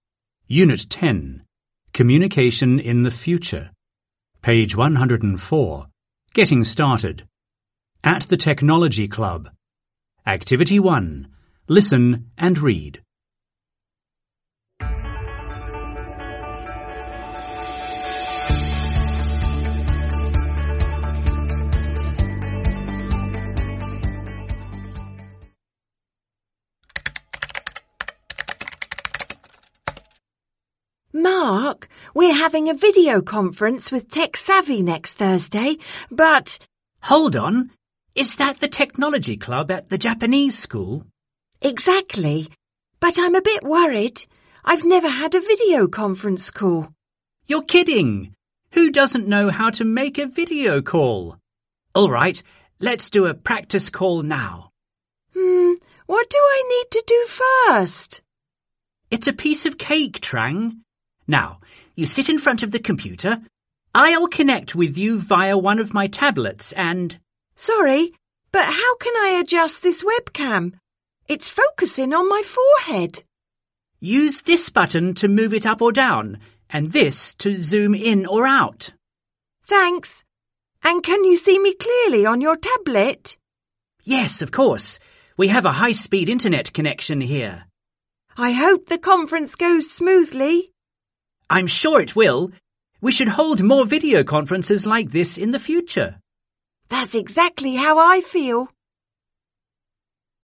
• Bài tập 1: Bạn sẽ nghe và đọc đoạn hội thoại giữa 2 người bạn về việc chuẩn bị cho một cuộc gọi video với câu lạc bộ công nghệ.